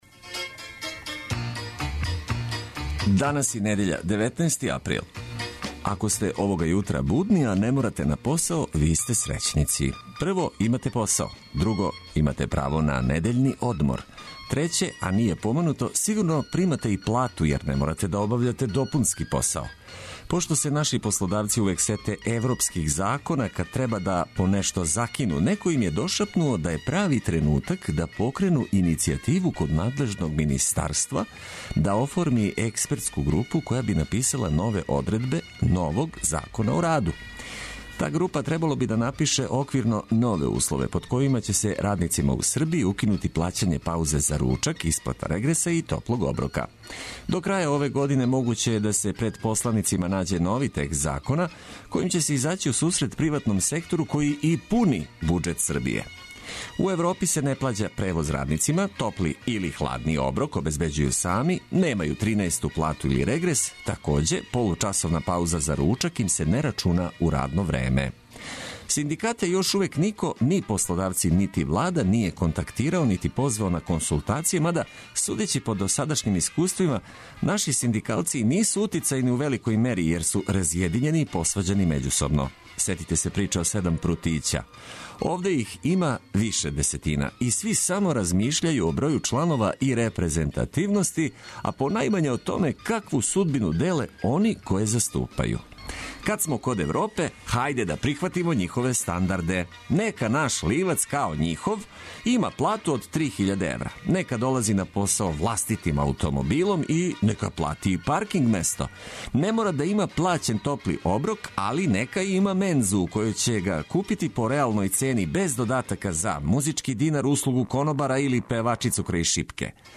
Нека недеља почне уз много музике и ведре теме.